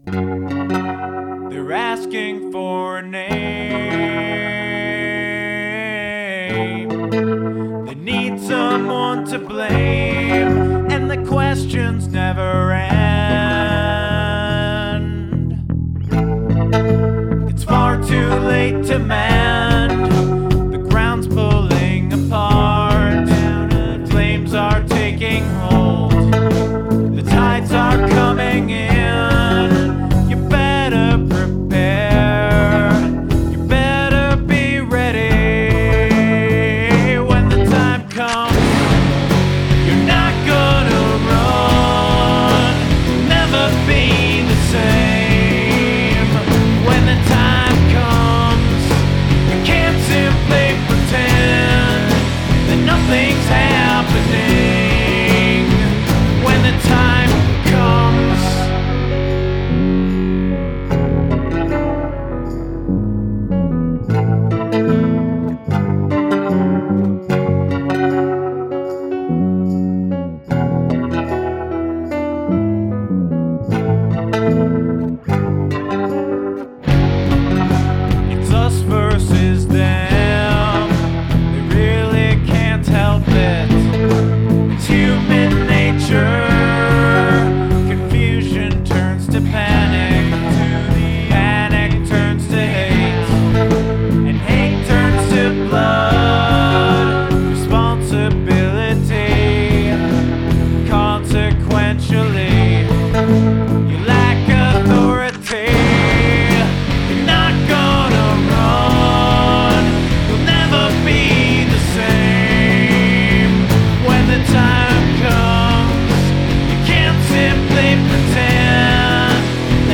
Use of the sound of gunfire